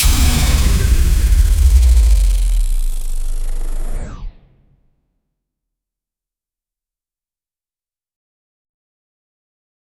sparkling-ricochet-alien--cndmnamz.wav